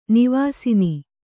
♪ nivāsini